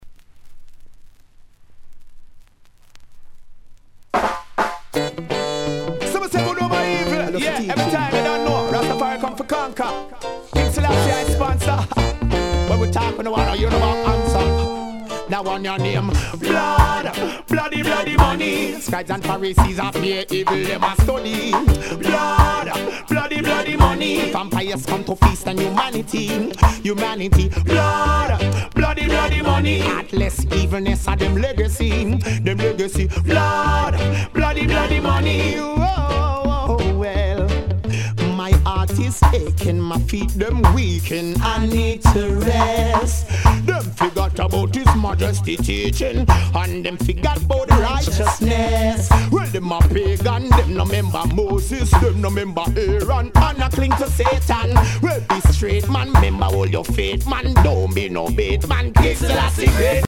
Please post only reggae discussions here